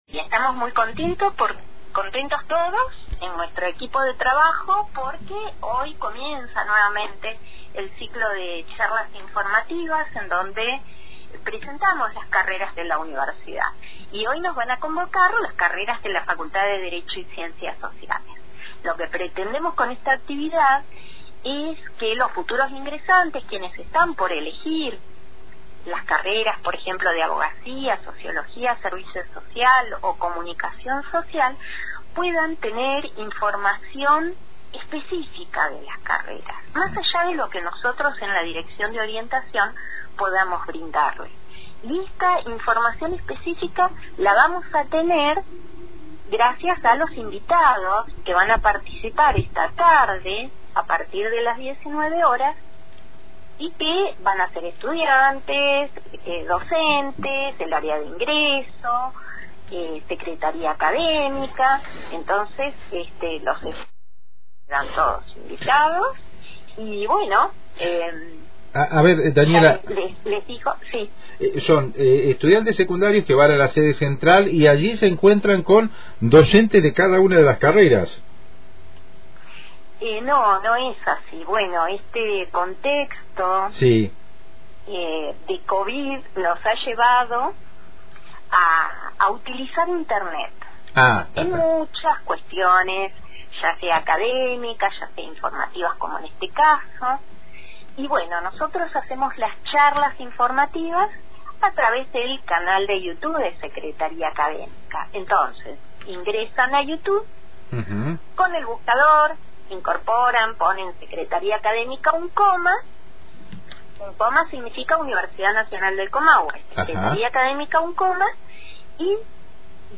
dialogó con Antena Libre sobre la actividad que inicia hoy con la Facultad de Derecho y Ciencias Sociales de Roca.